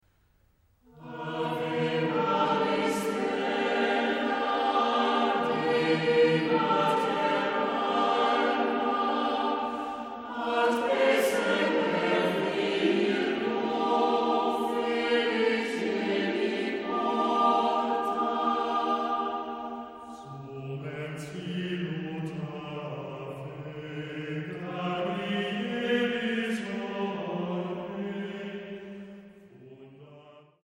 SATB (4 voices mixed) ; Full score.
Hymn (sacred).
Consultable under : 20ème Sacré Acappella